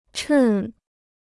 趁 (chèn) 中国語無料辞典